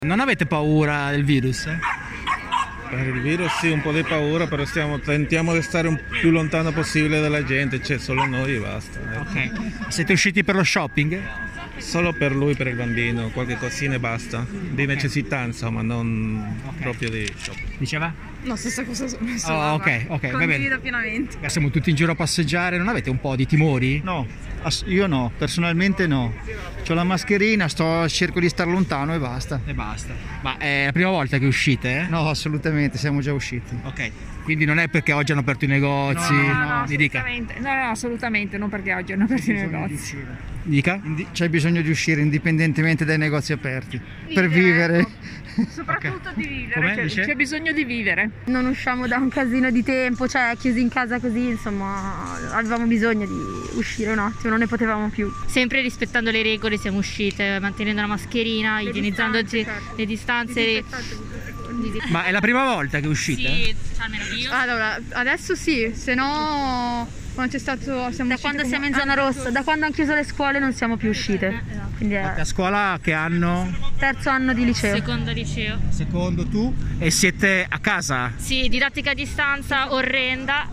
“Ne approfittiamo per stare un po’ all’aperto e fare qualche acquisto” hanno detto le persone intervistate.